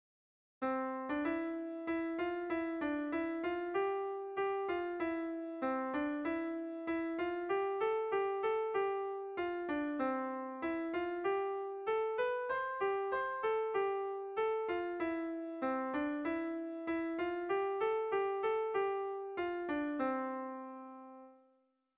Irrizkoa
Zortziko txikia (hg) / Lau puntuko txikia (ip)
A1A2BA2